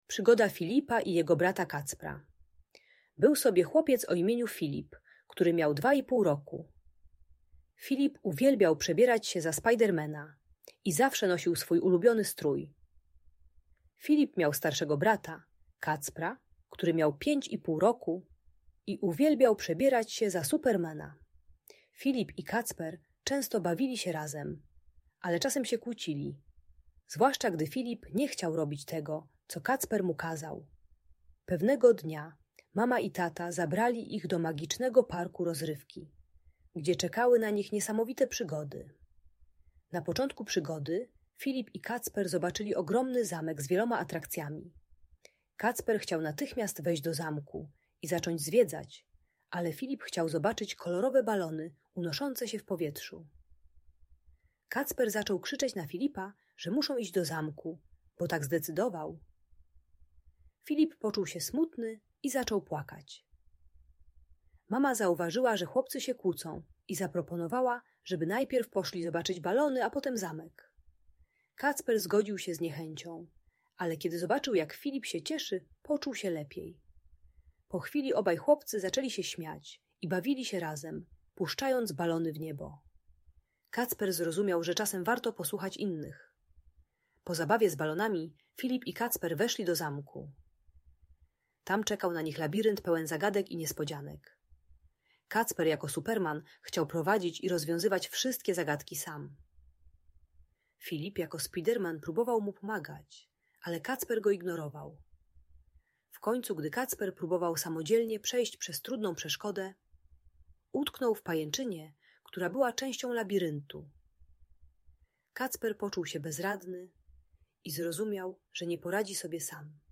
Przygoda Filipa i Kacpra - Opowieść o Współpracy i Przyjaźni - Audiobajka dla dzieci